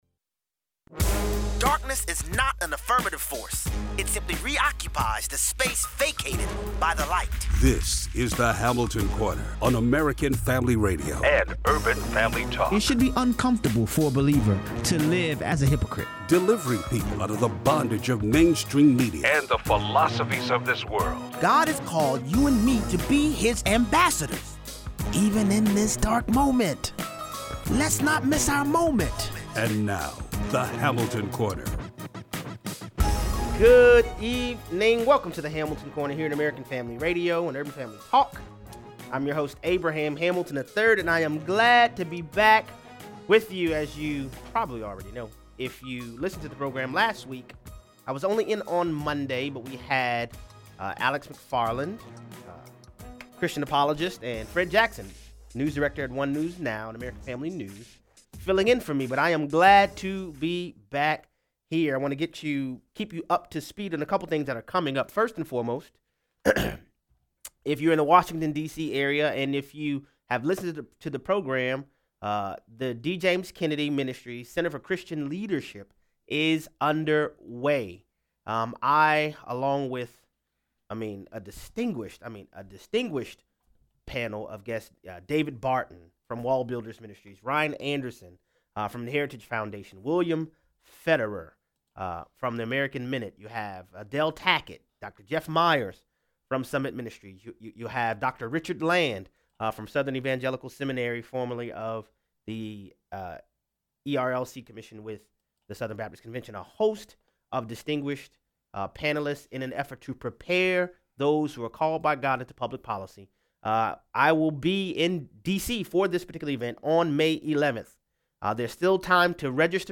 Is President Trump going to be the next recipient of the Nobel Peace Prize. 0:43 - 0:60: The White House Correspondents’ dinner reveals why there is a Trump train. Callers weigh in.